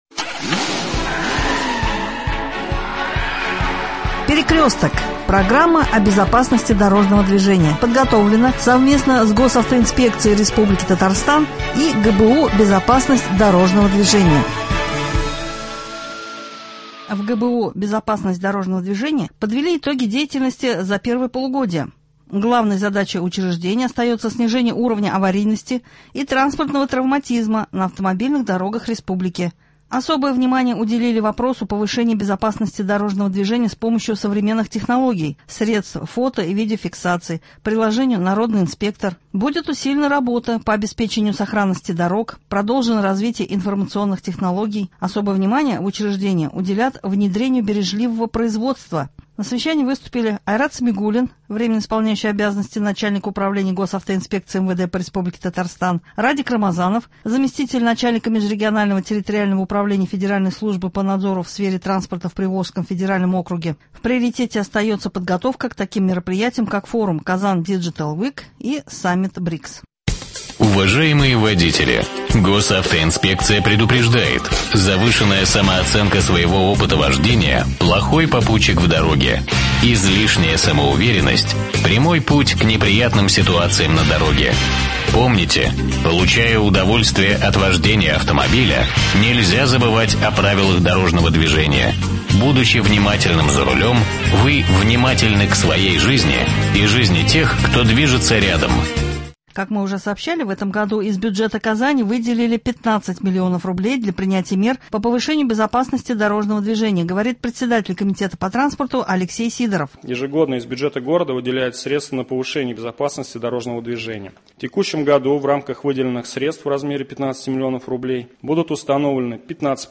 Говорит председатель комитета по транспорту Алексей Сидоров.
Об этом сообщил председатель комитета внешнего благоустройства Игорь Саляхутдинов.